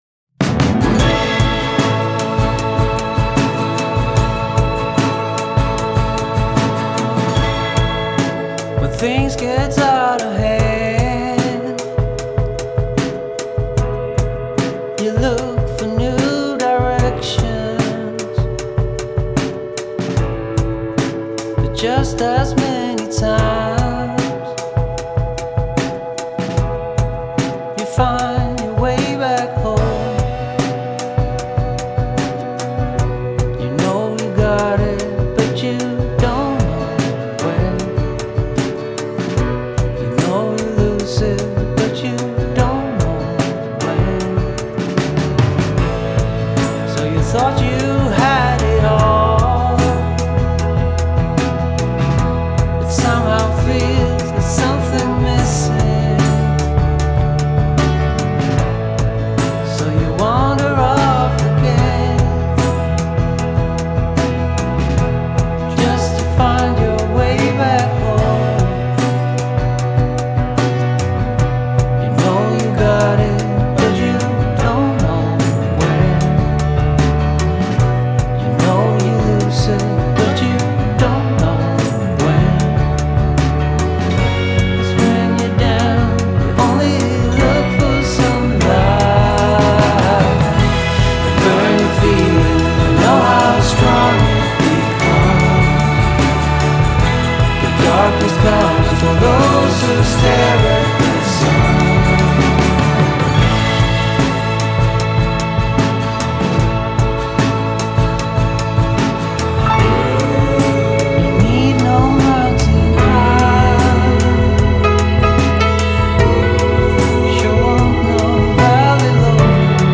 understated soft-rock melancholia